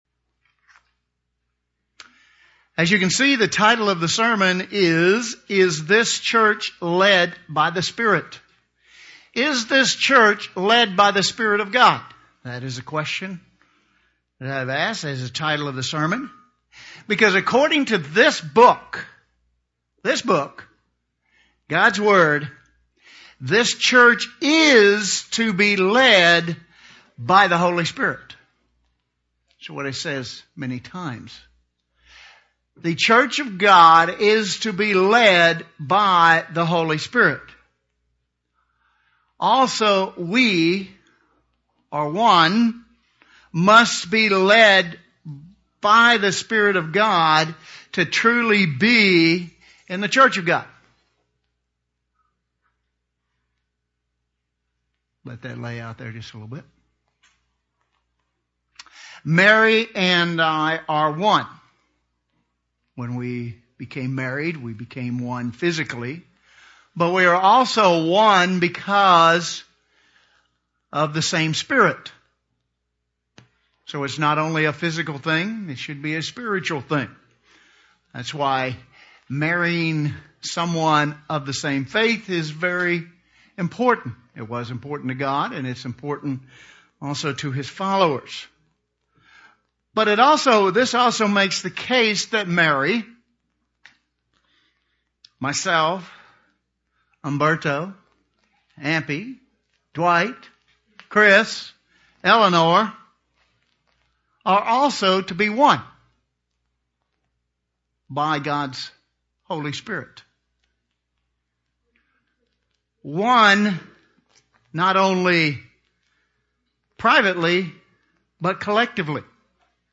If you aren't being led by the Spirit, can you change that? sermon Transcript This transcript was generated by AI and may contain errors.